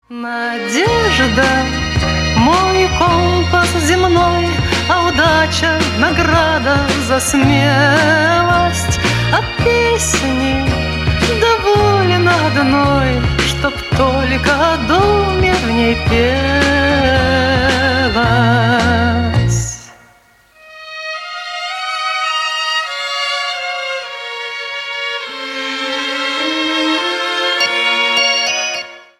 романс
ретро